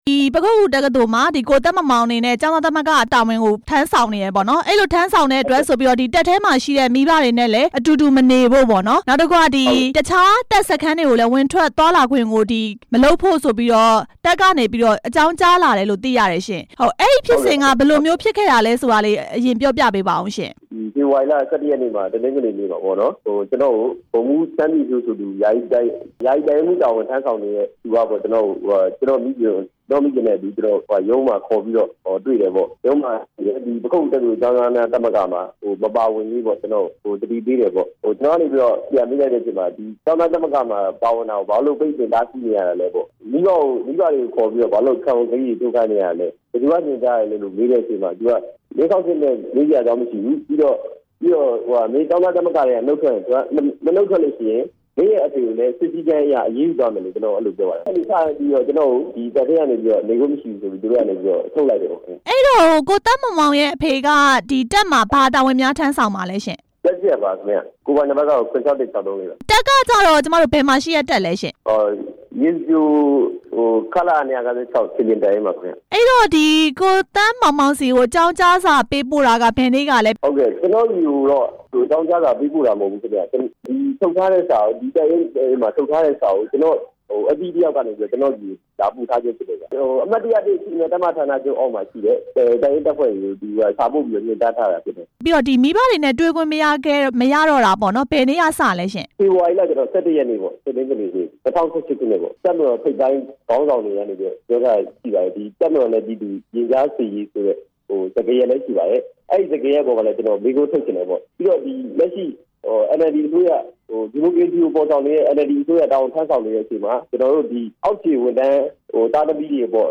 တပ်မိသားစုနဲ့ အတူနေခွင့်မရတဲ့ ကျောင်းသားကို မေးမြန်းချက်